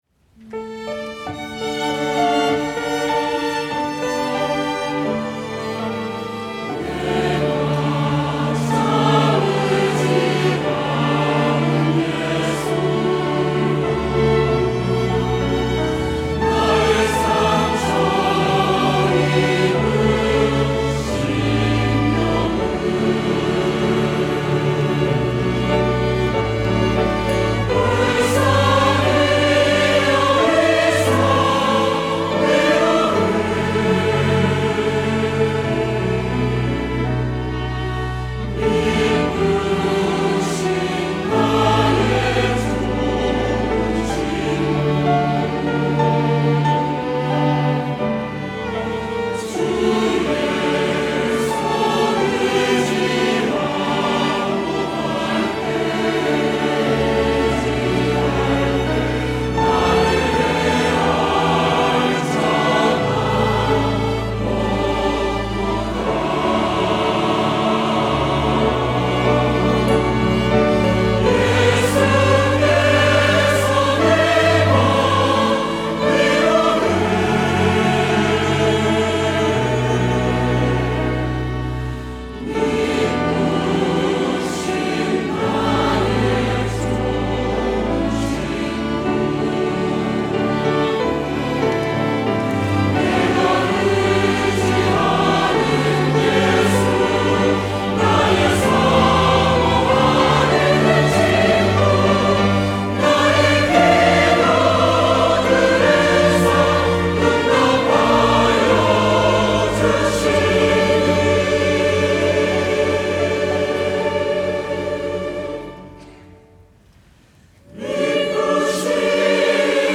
호산나(주일3부) - 내가 참 의지하는 예수
찬양대